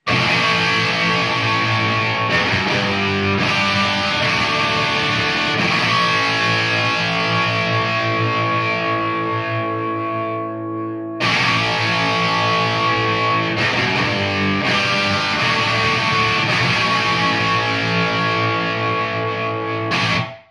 J'aimerai essayer une guitare avec des P90 en crunch,
Les Paul Jr de 1959, branchée dans mon JVM205H
Les Paul Jr et Marshall JVM205H.mp3